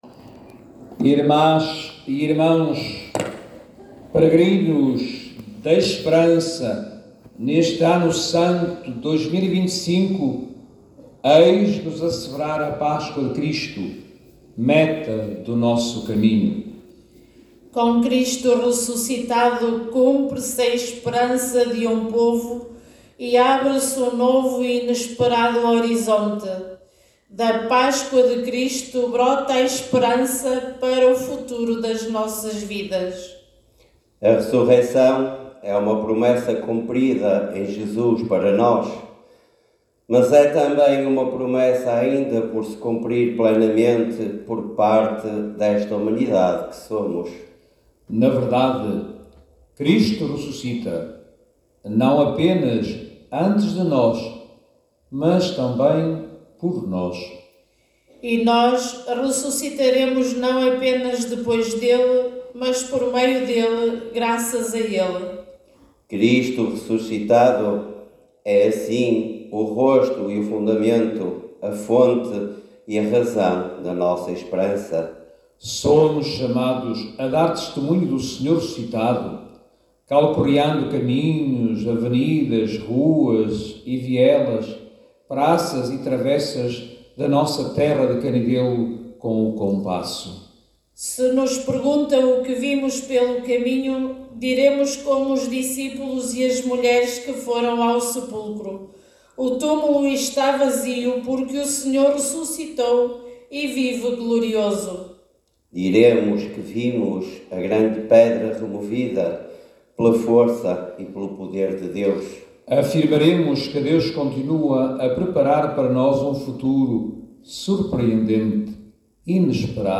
Homilia Eucaristia de Encerramento
Homilia_Pascoa.mp3